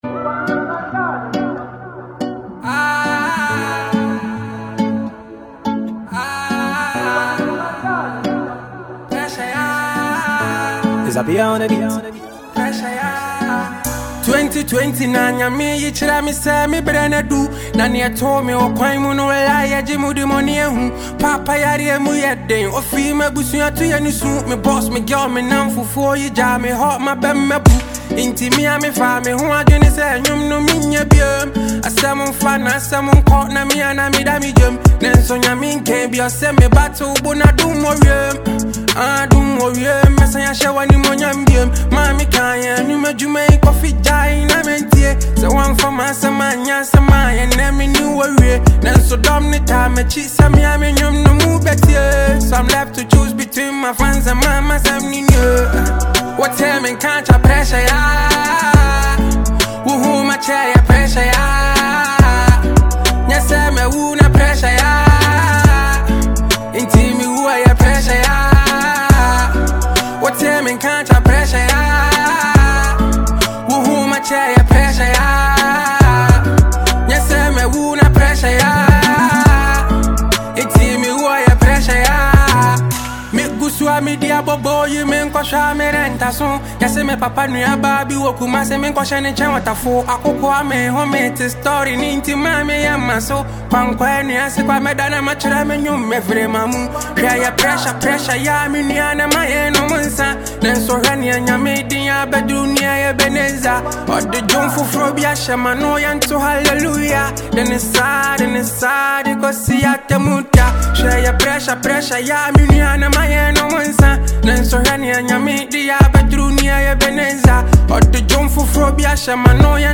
a Ghanaian singer
a solo track